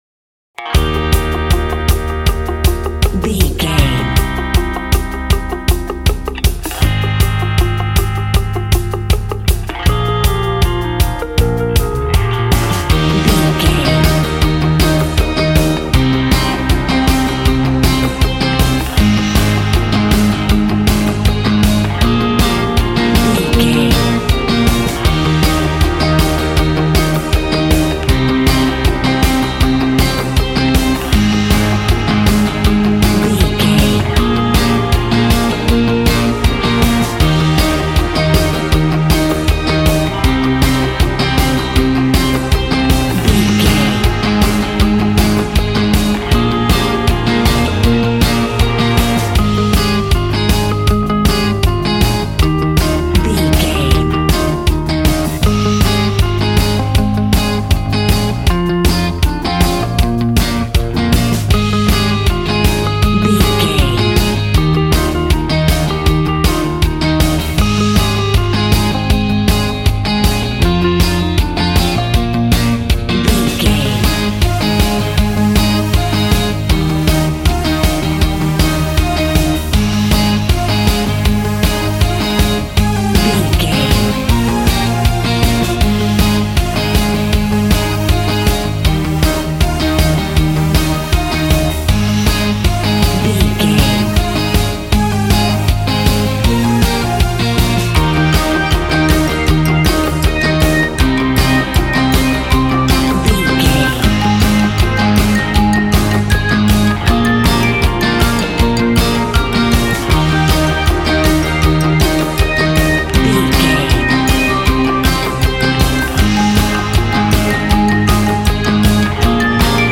Ionian/Major
E♭
groovy
powerful
organ
drums
bass guitar
electric guitar
piano